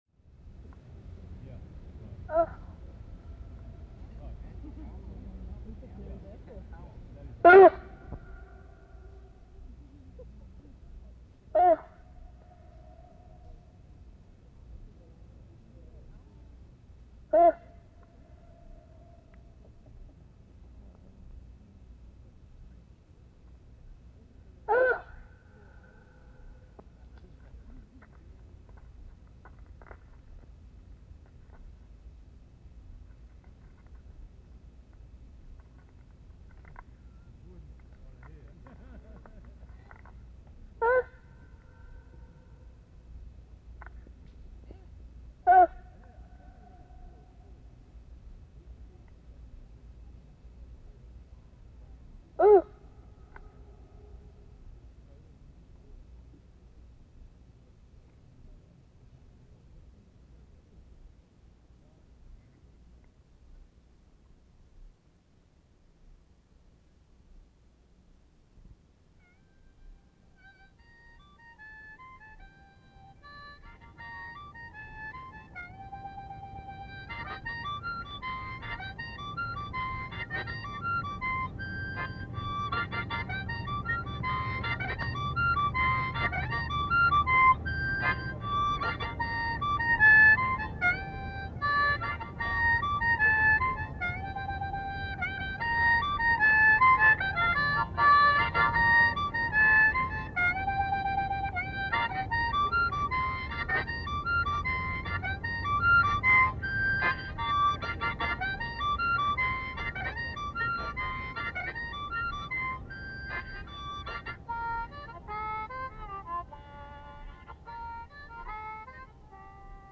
Audio work composed of recordings from community gatherings in 1992; 26:33 minutes
Taking the form of a large megaphone constructed primarily of wood and tanned moose hide and embedded with a loudhailer, the work was intended to amplify the voices of Indigenous people in Canada and was first activated at a gathering in a meadow at Johnson Lake in Banff National Park where a group of over sixty people joined to either take up the artist’s invitation to speak through the work to the land or to witness.